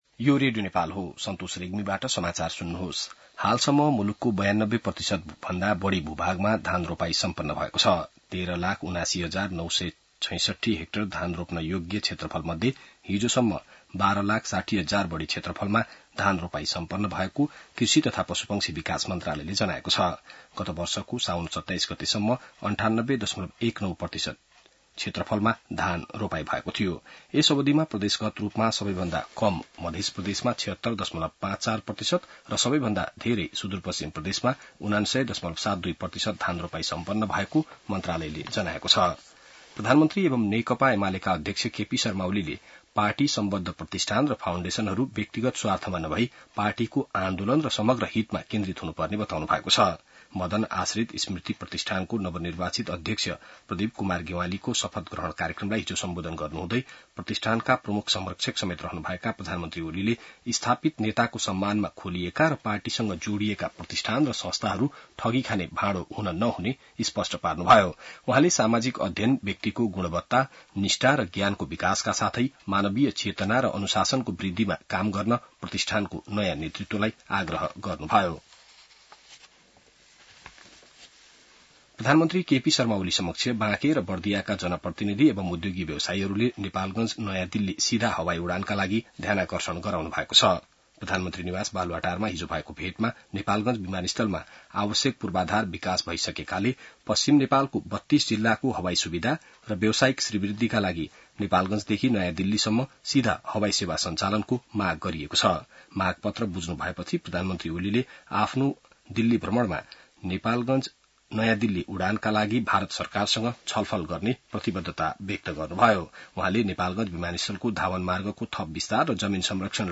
बिहान ६ बजेको नेपाली समाचार : २७ साउन , २०८२